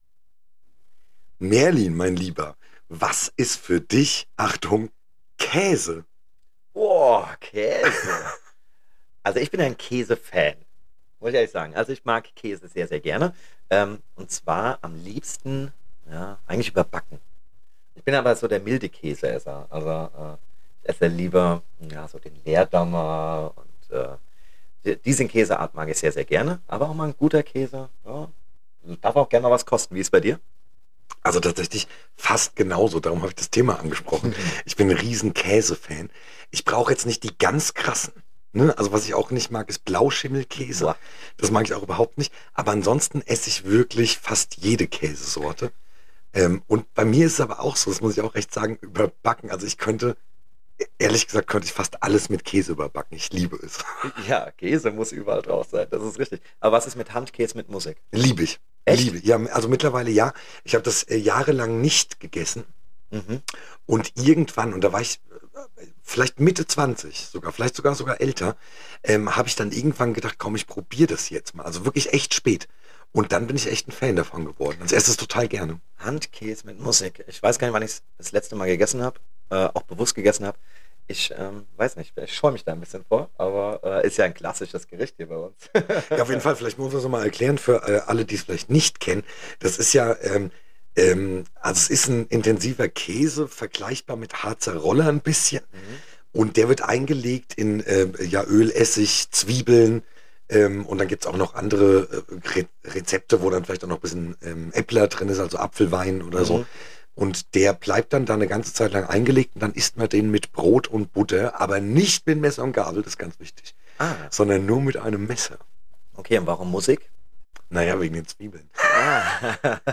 Spontan aus einem Pressetermin wurde diese "Sonderfolge" aufgenommen.